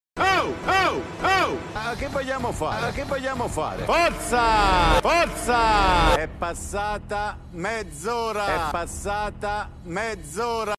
Categoria Sveglia